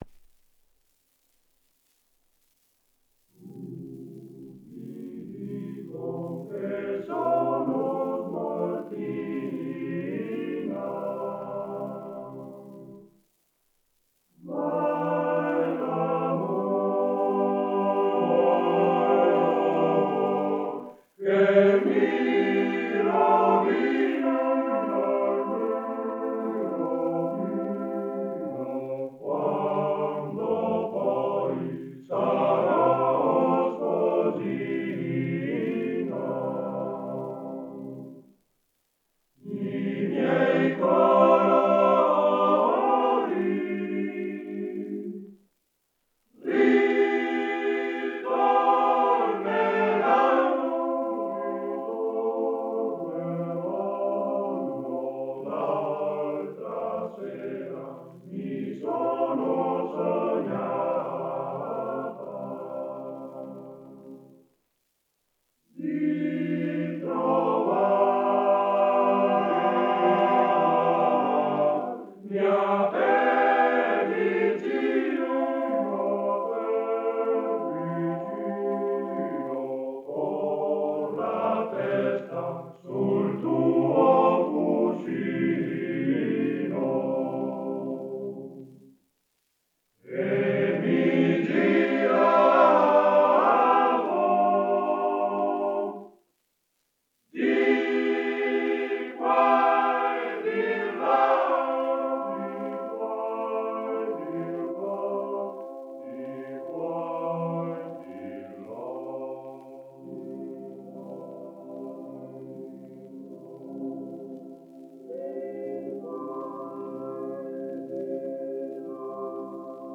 Arrangiatore: Pigarelli, Luigi
Esecutore: Coro della SAT